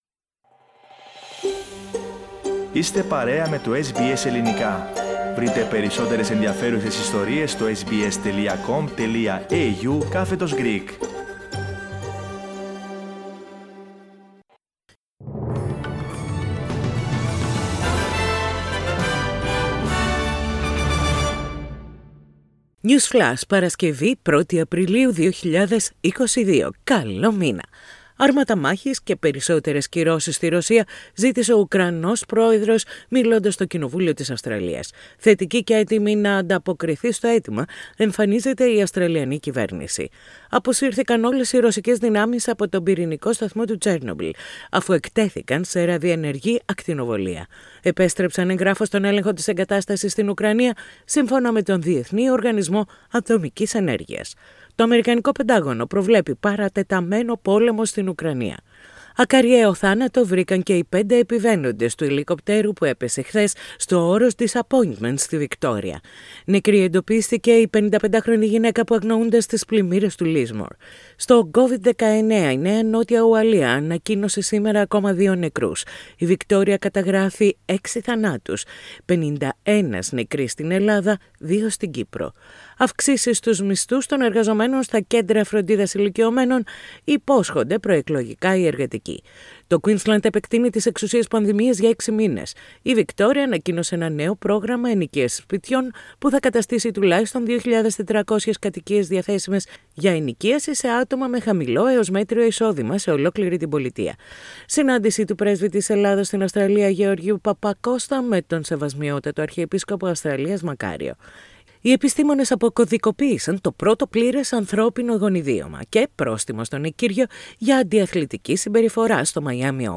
News Flash - Σύντομο Δελτίο Ειδήσεων - Παρασκευή 1.4.22